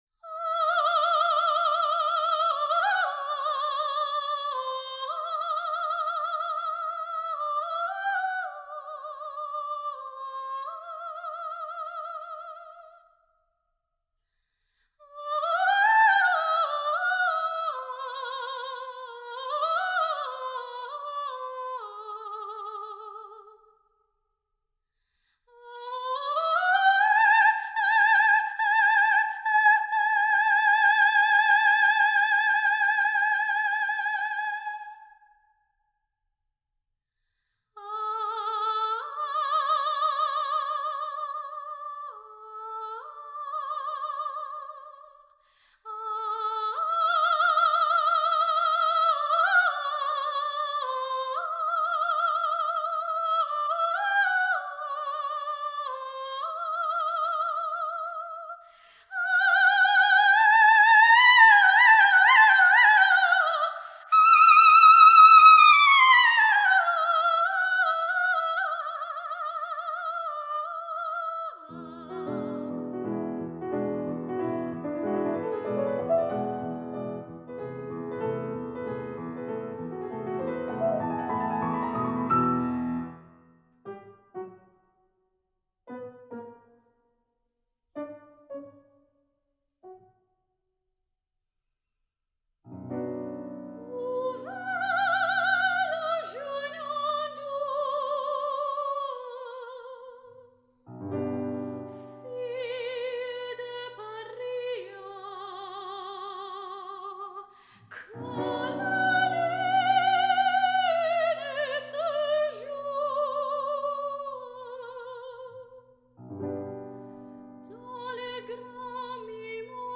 Based on the opera by Léo Delibes